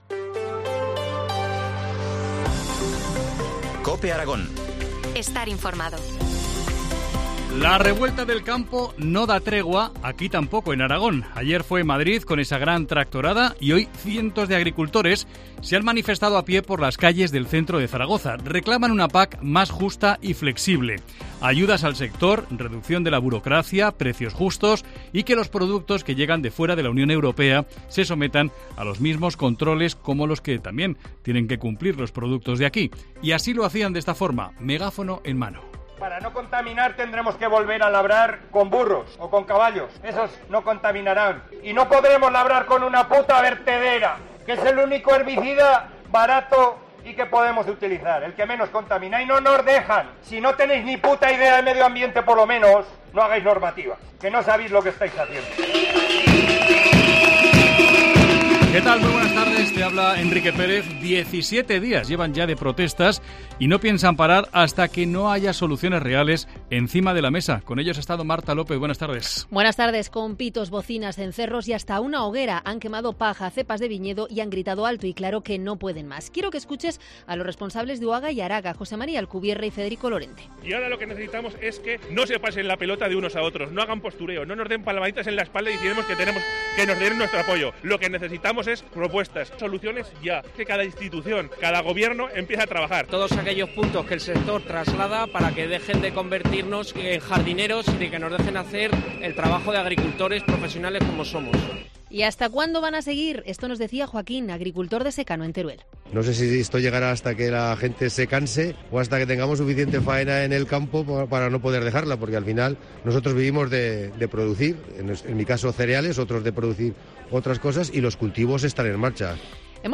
Pitos, cencerros y hogueras: cientos de agricultores se manifiestan en Zaragoza
“No tenéis ni puta idea de medio ambiente, por lo menos no hagáis normativa, que no sabéis el daño que estáis haciendo”, gritaban megáfono en mano.
Con pitos, bocinas, cencerros y hasta una hoguera.